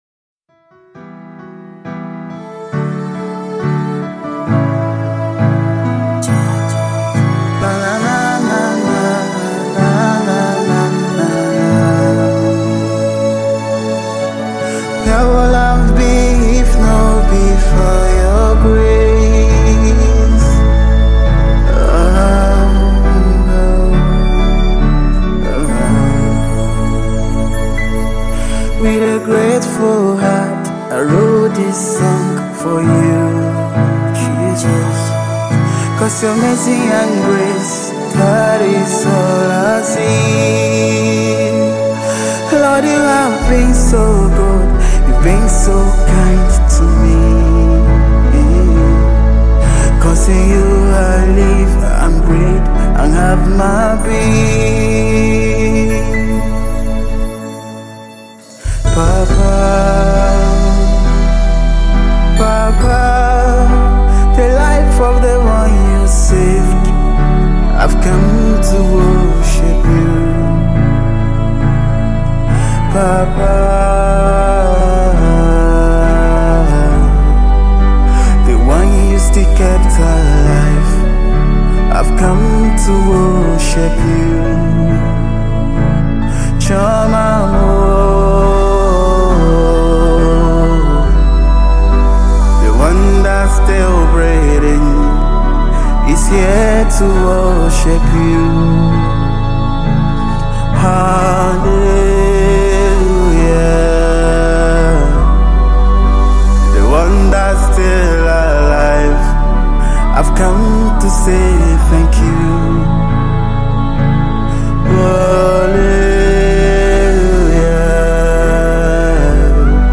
Amazing Gospel Singer
is a heartfelt song